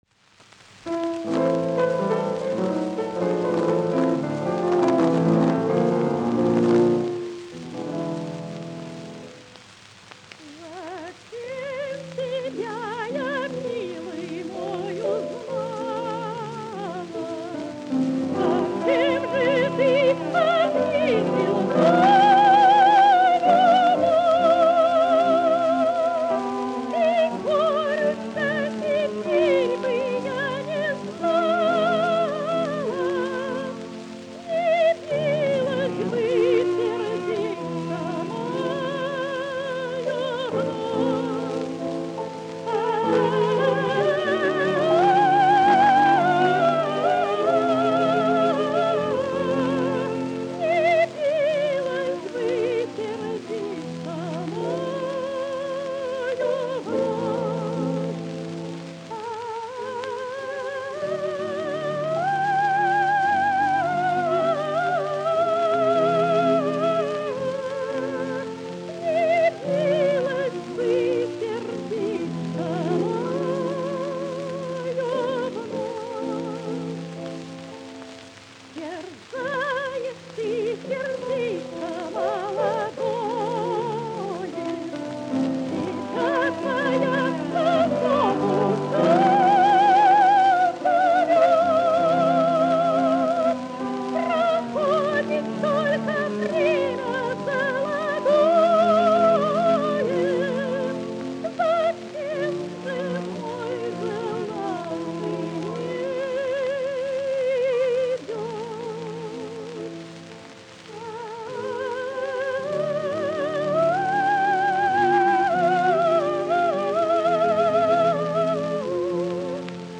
ЛЮБЛЮ ШУРШАЩИЕ, ОНИ ТАКИЕ НОСТАЛЬГИЧЕСКИЕ.